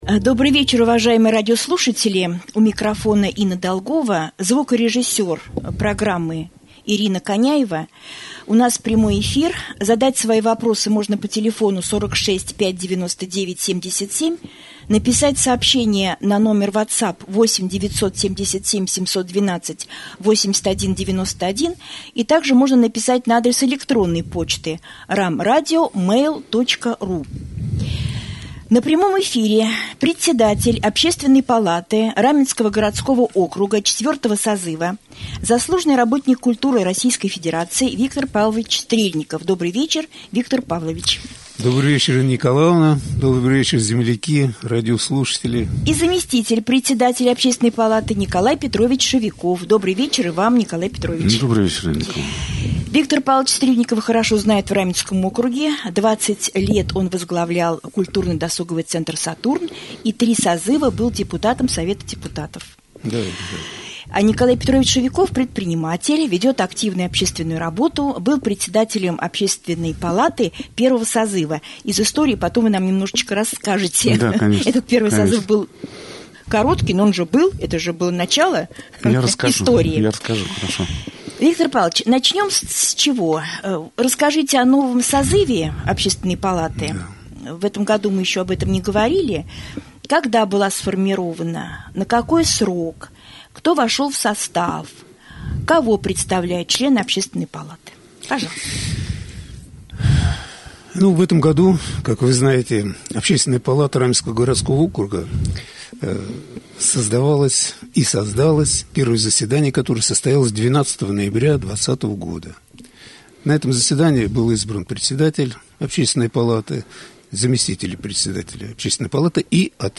Гости студии
во время прямого эфира обсудили темы: